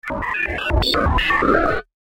دانلود آهنگ رادیو 3 از افکت صوتی اشیاء
جلوه های صوتی